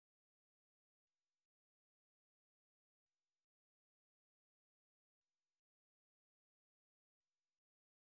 projector_peep.wav